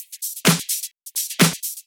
Index of /VEE/VEE Electro Loops 128 BPM
VEE Electro Loop 149.wav